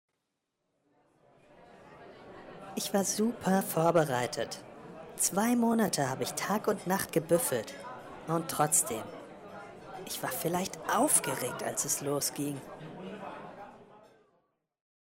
Yesterday was Theresa’s final exam and now she is telling her friend about it: